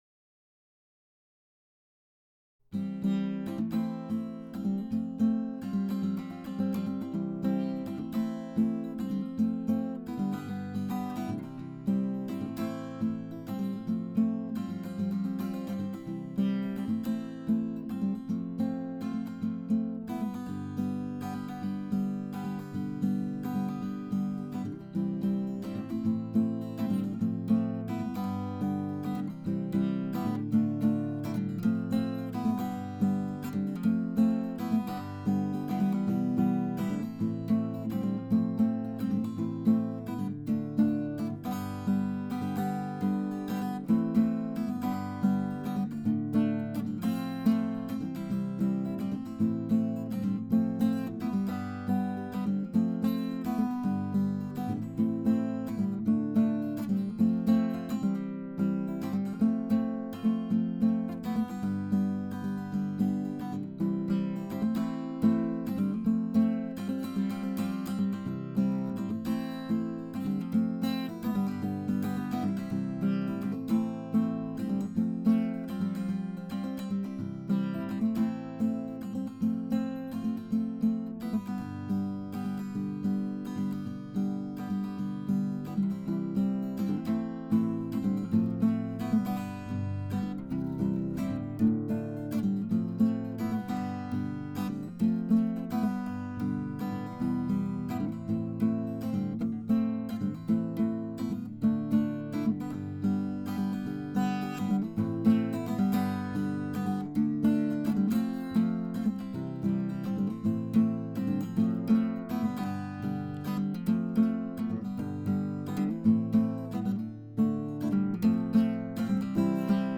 acousticguitar.wav